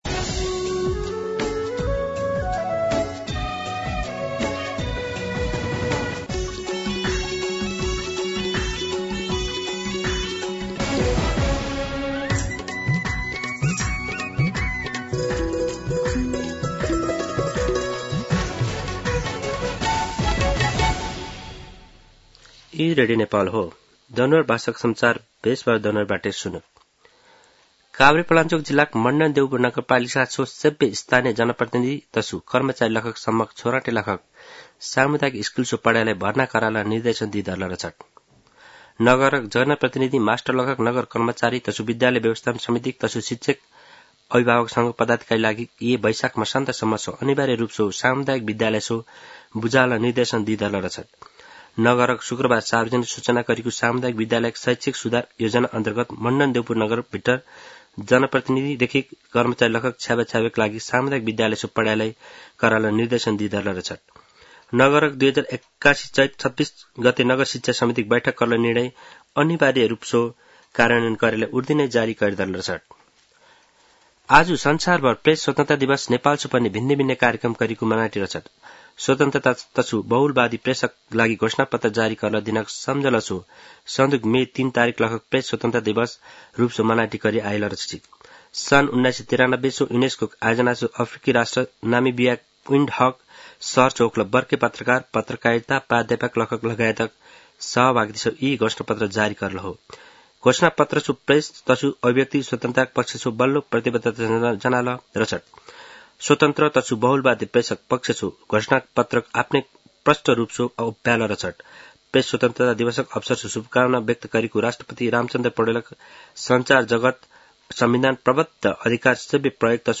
दनुवार भाषामा समाचार : २० वैशाख , २०८२
Danuwar-News-20.mp3